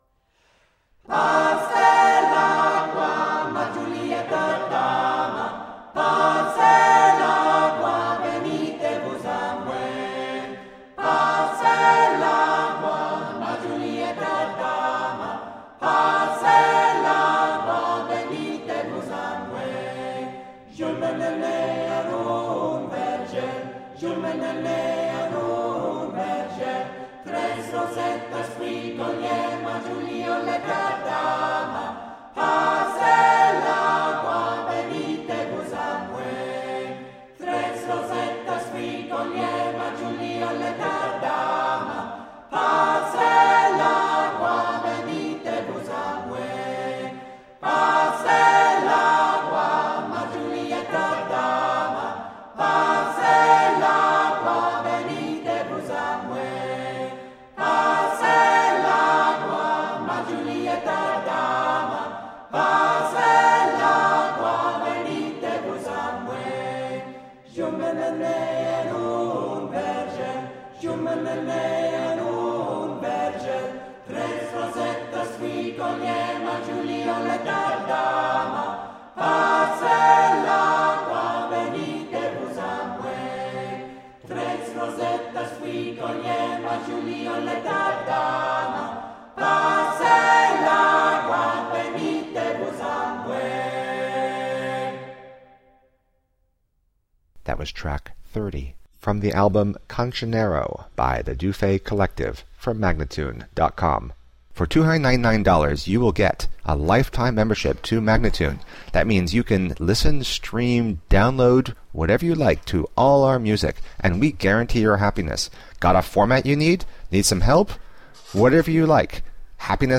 Classical, Renaissance, Classical Singing
Flute, Lute, Viola da Gamba